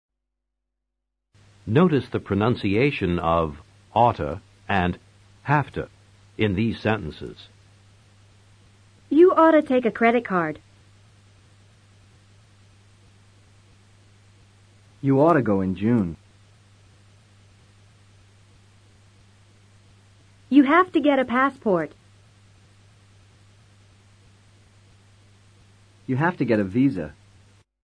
Pronunciación de OUGHT TO y HAVE TO
Notice the pronunciation of OUGHT TO and HAVE TO